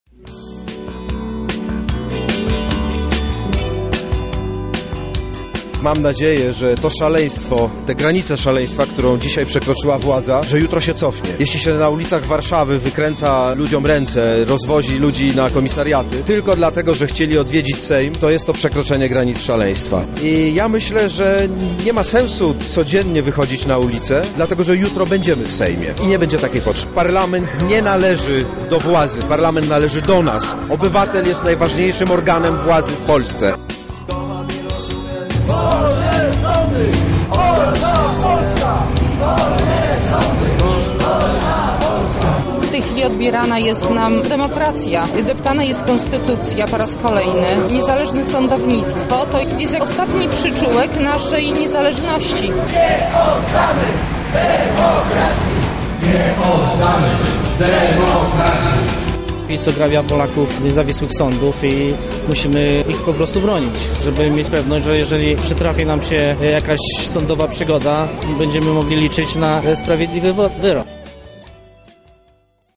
Z protestującymi rozmawiał nasz reporter.